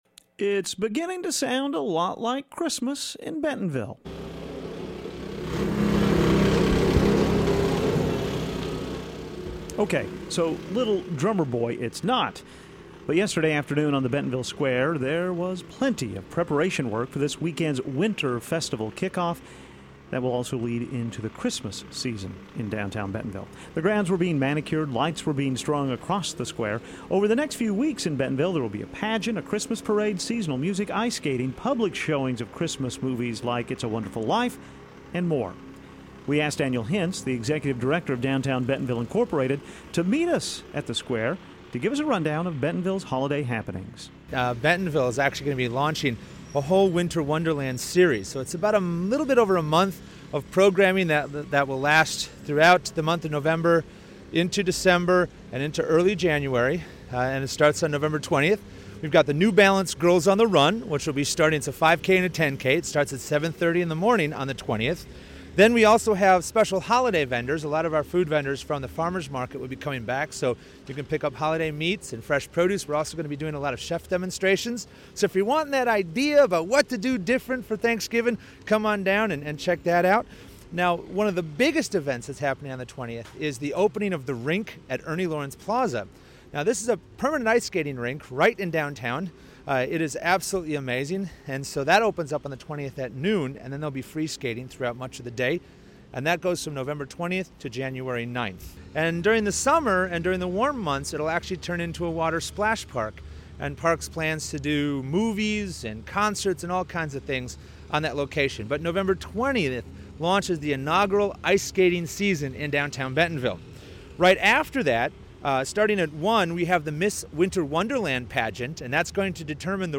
This weekend a nearly month-long holiday season kicks off in downtown Bentonville. We went to the city square to learn more.